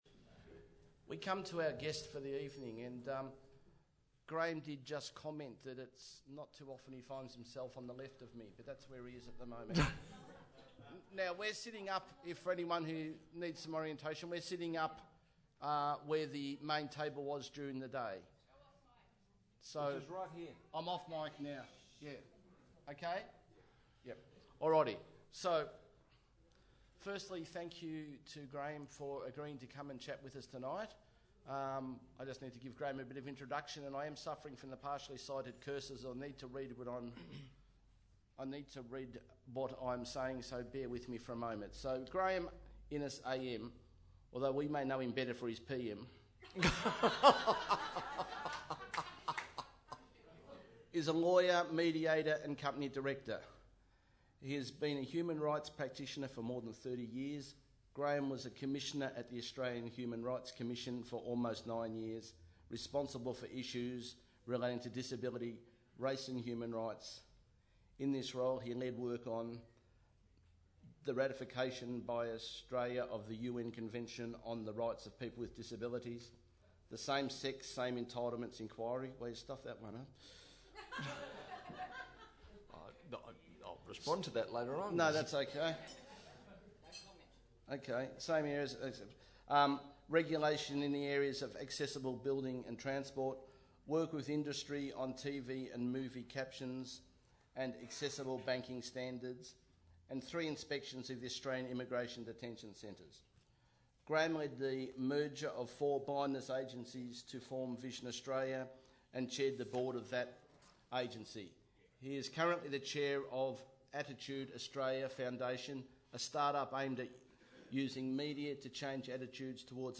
BCA_2016_Vic_Conf_Dinner.mp3